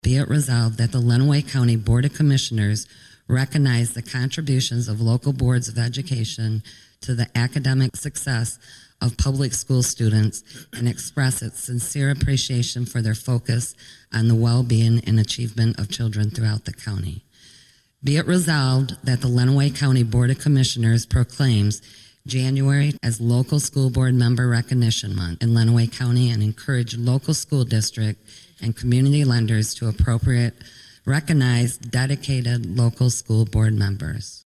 Adrian, MI – The Lenawee County Board of Commissioners read a proclamation at their meeting Wednesday afternoon, declaring January as School Board Recognition Month. Commissioner Dawn Bales, who served on a school board for 8 years, read the proclamation.